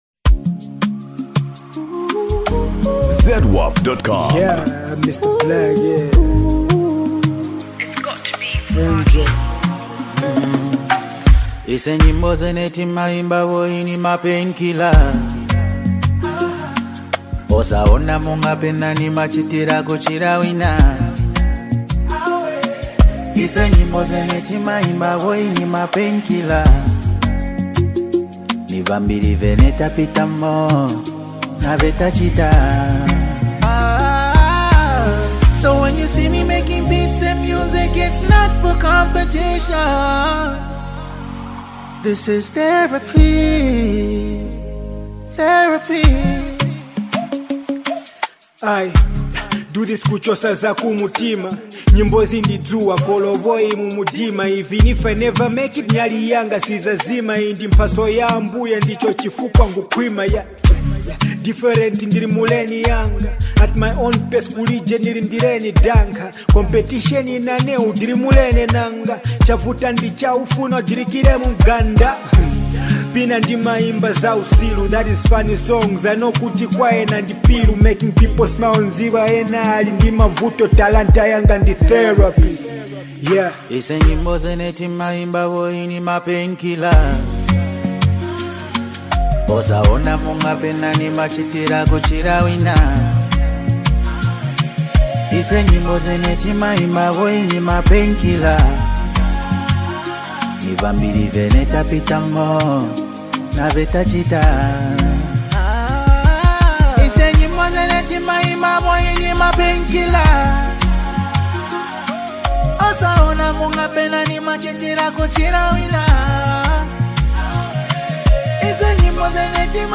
Genre: Afro Pop, Zambia Songs